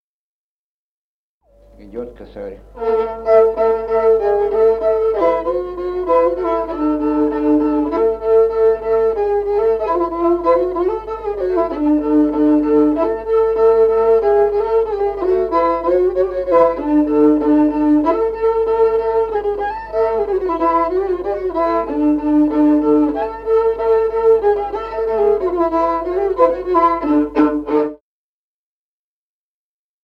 Музыкальный фольклор села Мишковка «Косарь», партия 2-й скрипки.